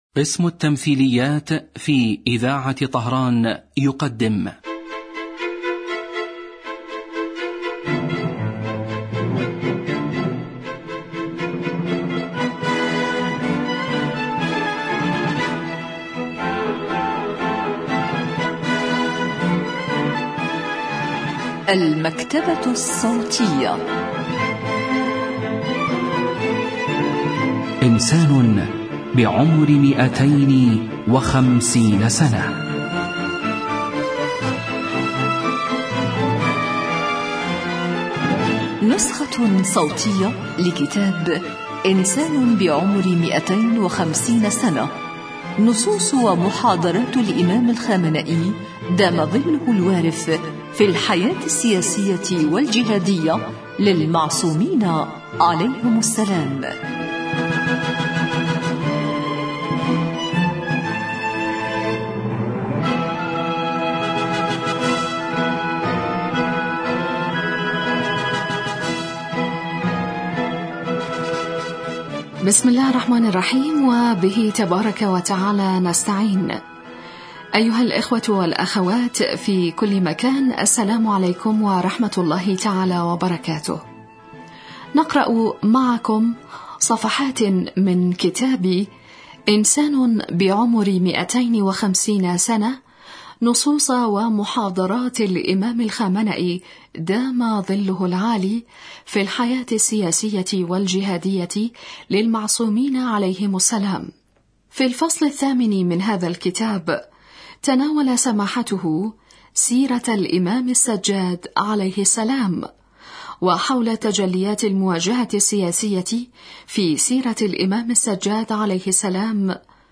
الكتاب الصوتي